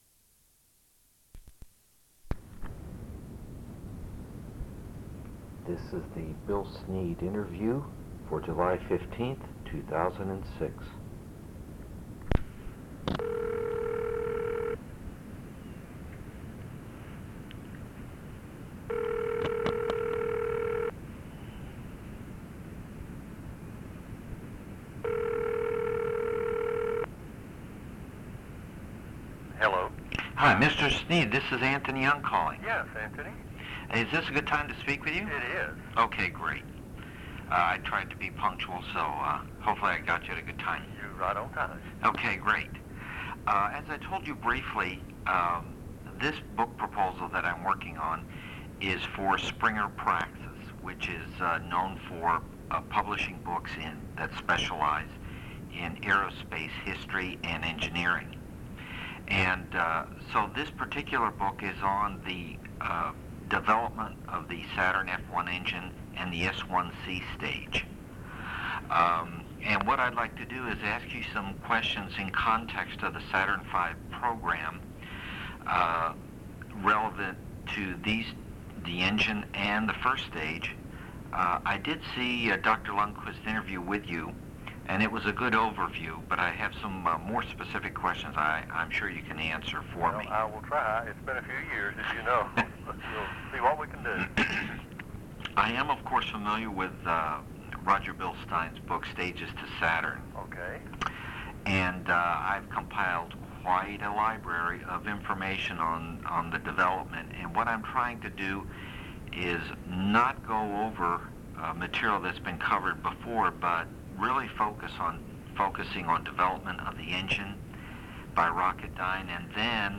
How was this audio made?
Audiocassettes